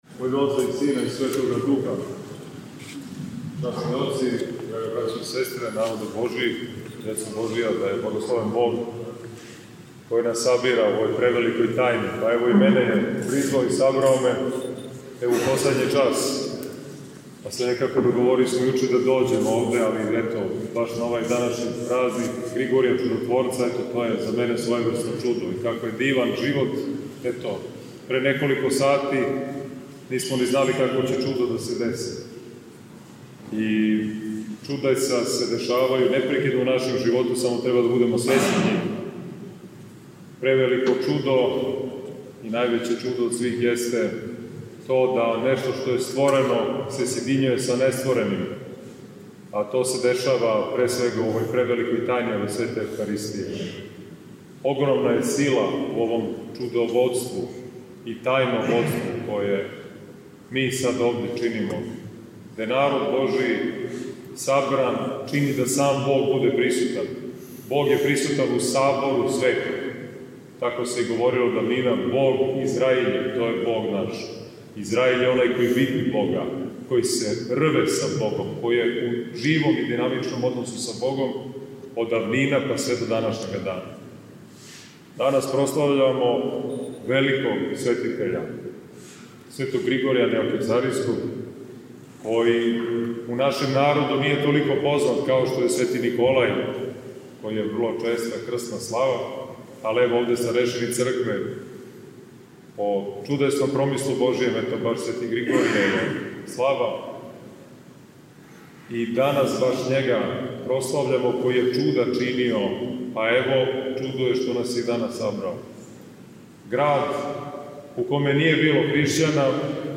Звучни запис беседе
3 beseda vl. Ilarion u Sv. Pantelejmonu.mp3